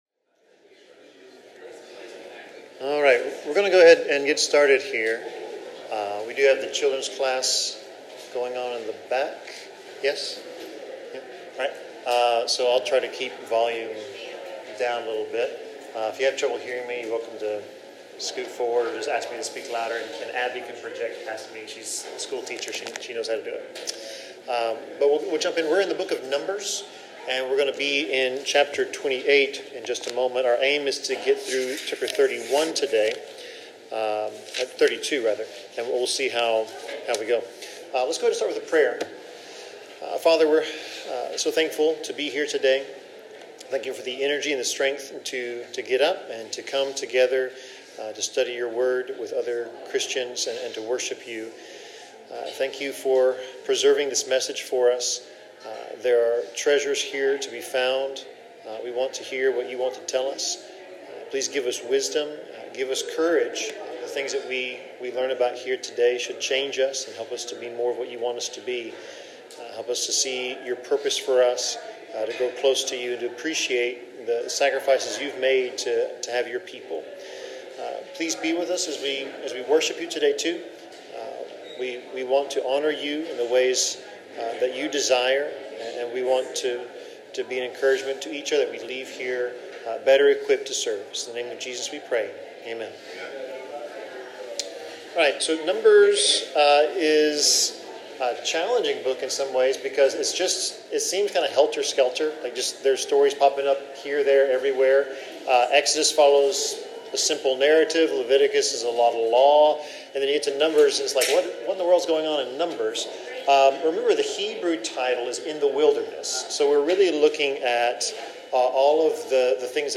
Bible Class: Numbers 28-31
Service Type: Bible Class